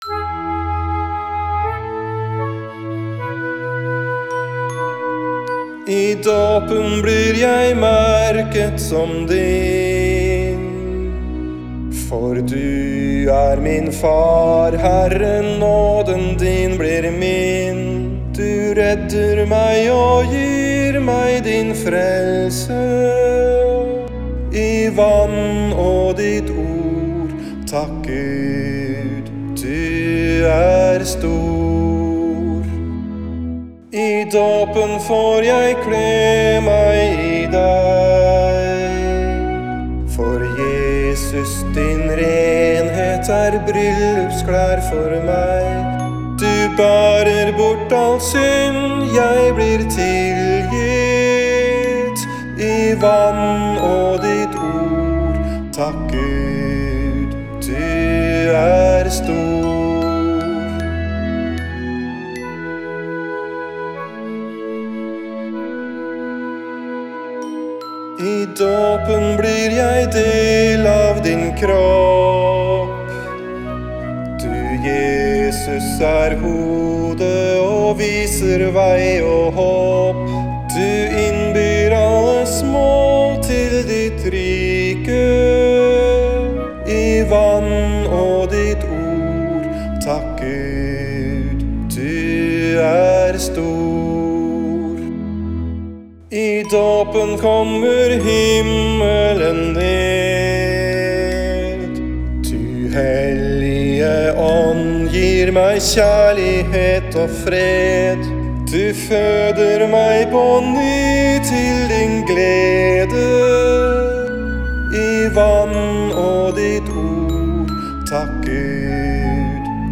Ny dåpssalme: «I dåpen»
En nyskrevet dåpssalme tar utgangspunkt i de sju gavene vi får i dåpen, slik de beskrives på DELKs temaside om dåp. Her kan du lytte til en enkel innspilling, laste ned tekst og noter og bli kjent med hvordan teksten er bygd opp.